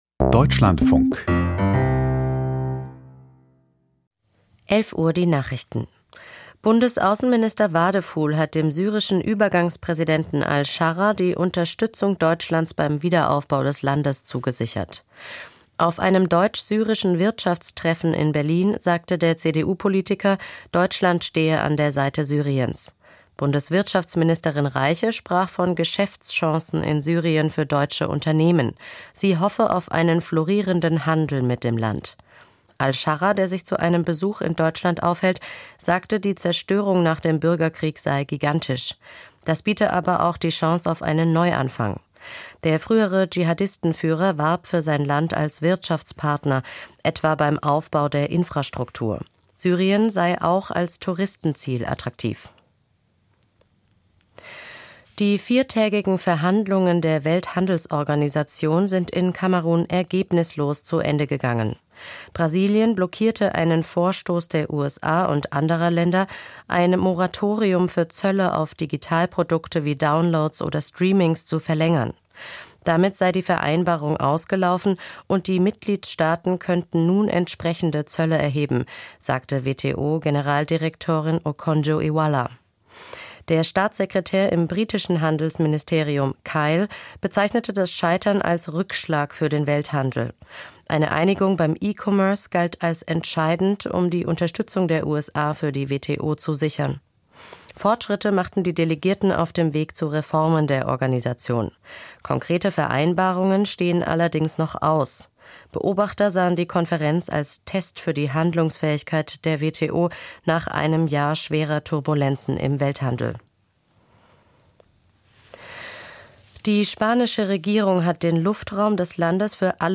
Die Nachrichten vom 30.03.2026, 11:00 Uhr
Aus der Deutschlandfunk-Nachrichtenredaktion.